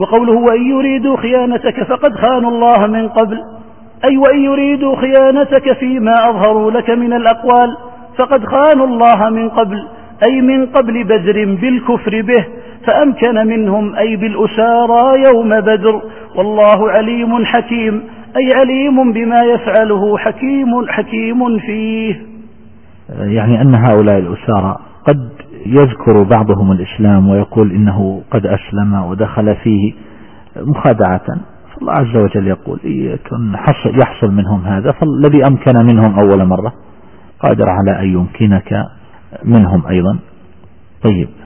التفسير الصوتي [الأنفال / 71]